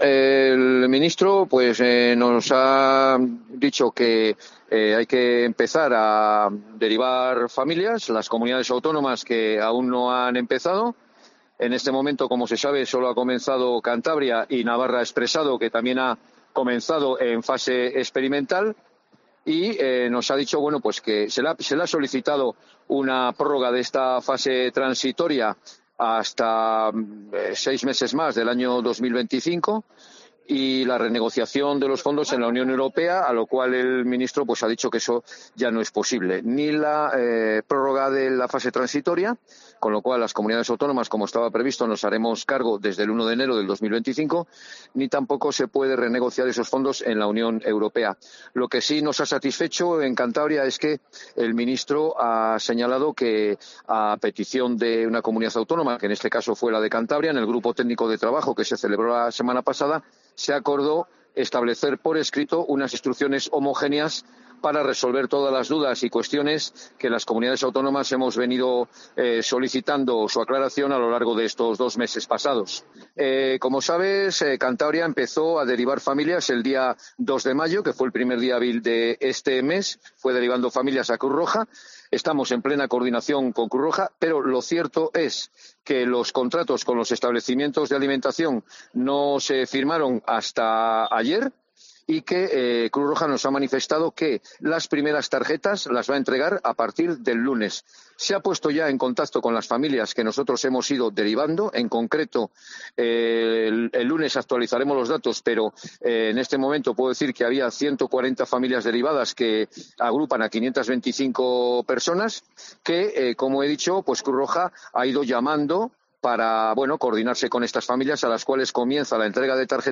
Eduardo Rubalcaba, director general de dependencia